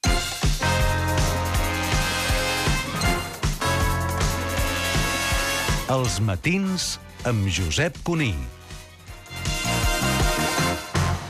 Indicatiu del programa